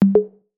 new_message.af98d8fbff11a8aece63.mp3